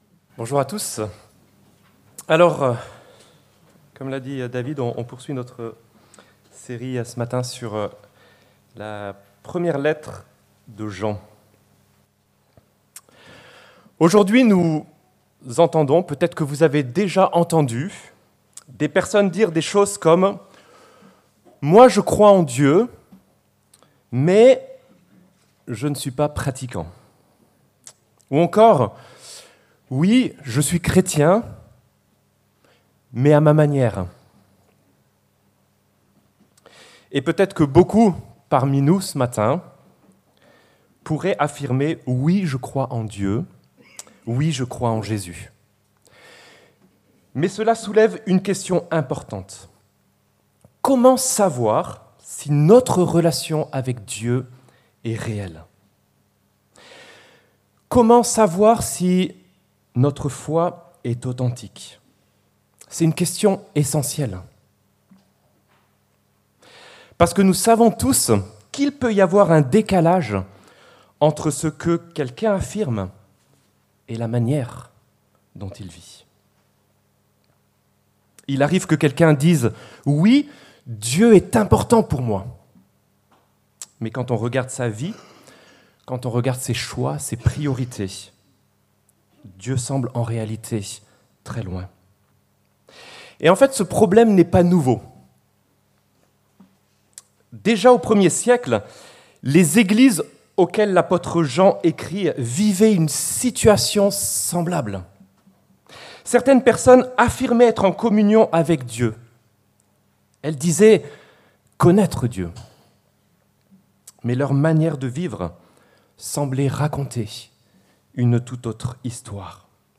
Comment considérons-nous le péché dans notre vie ? - Prédication de l'Eglise Protestante Evangélique de Crest sur la première épître de Jean